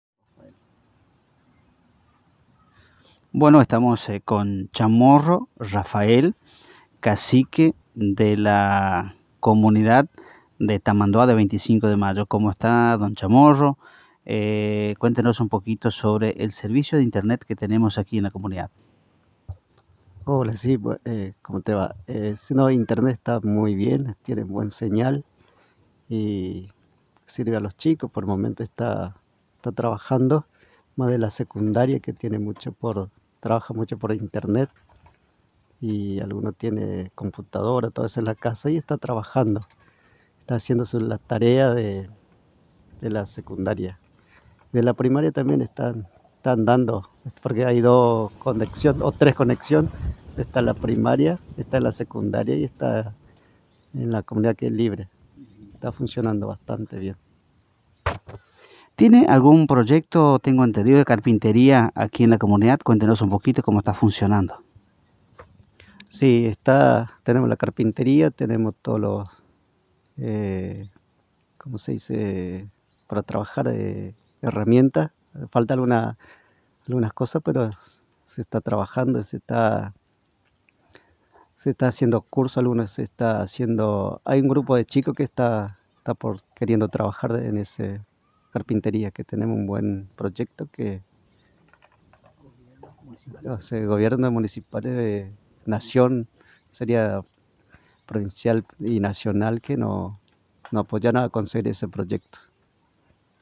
La Agencia de Noticias Guacurarí visitó esta mañana la Comunidad Tamanduá MBYA Guaraní de 25 de Mayo - Agencia de Noticias Guacurari